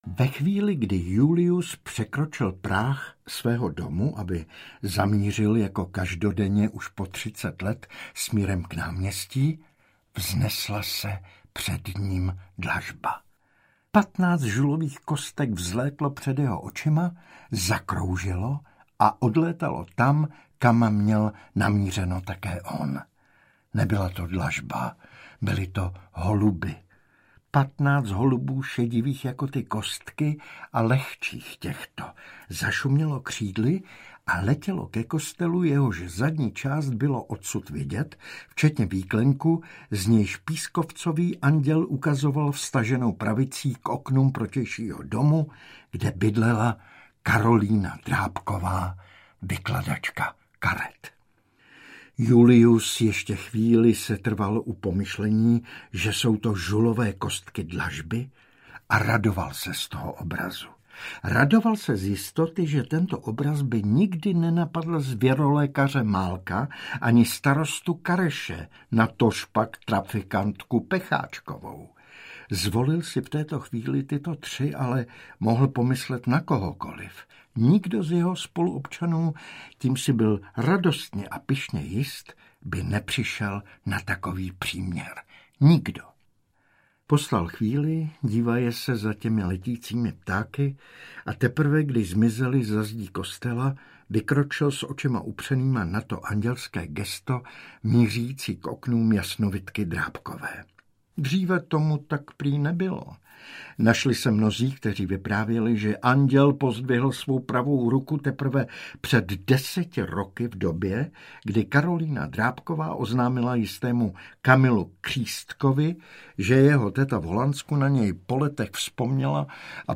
Julius a Albert audiokniha
Ukázka z knihy